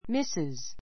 Mrs. Mrs A1 mísiz ミ セ ズ 略語 ～夫人 , ～さん ; ～先生 ⦣ 結婚 けっこん している女性への敬称. mistress （主婦の省略形）.